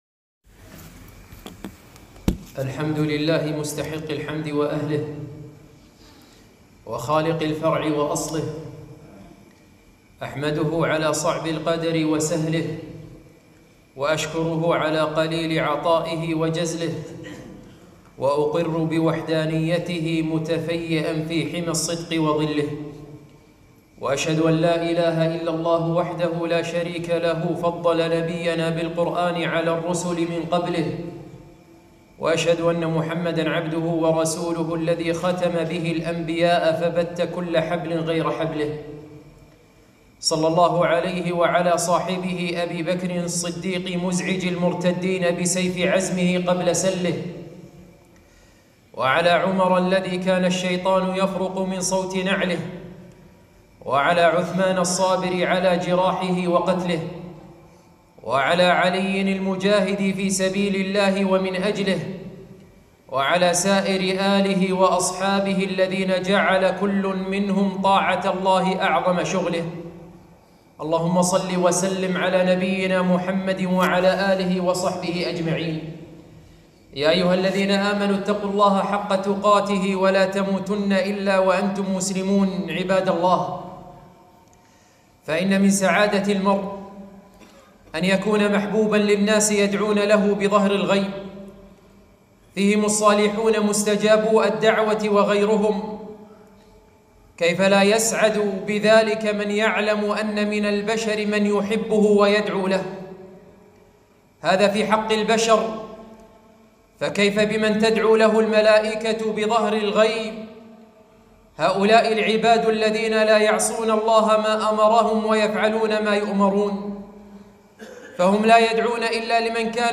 خطبة - كن ممن تدعو لهم الملائكة